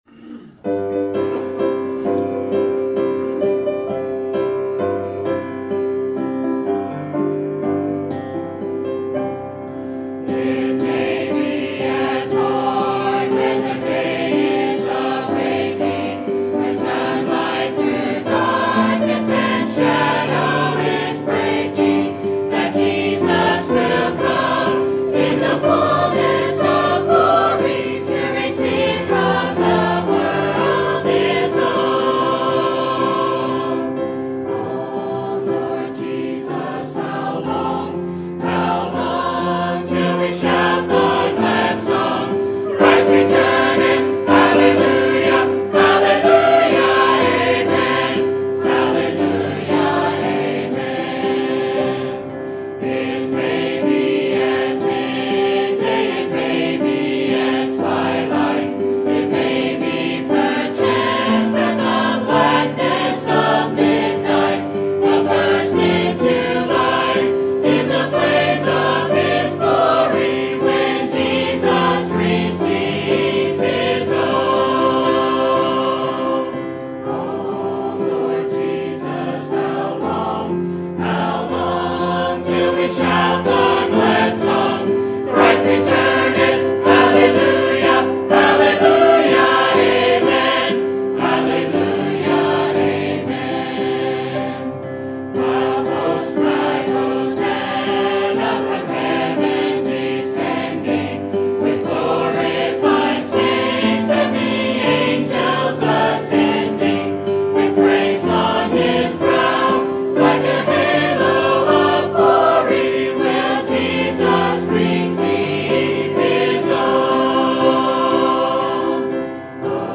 Sermon Topic: General Sermon Type: Service Sermon Audio: Sermon download: Download (19.55 MB) Sermon Tags: Job Trials Promise Preparation